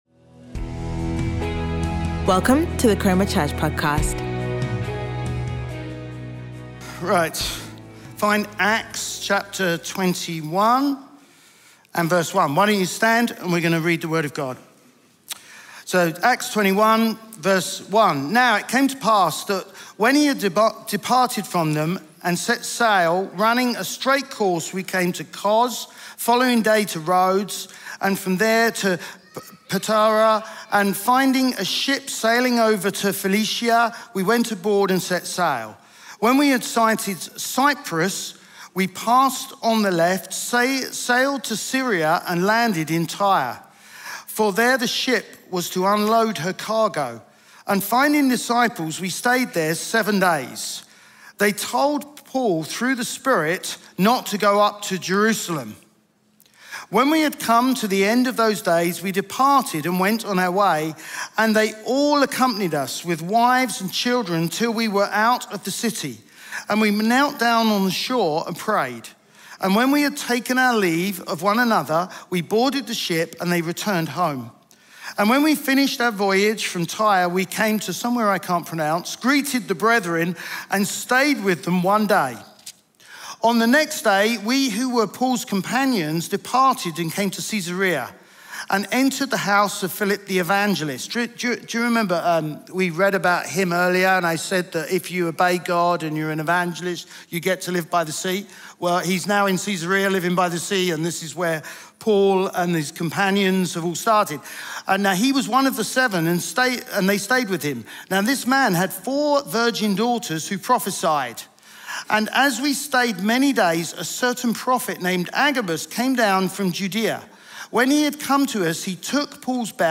Chroma Church - Sunday Sermon